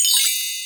badge-appear.mp3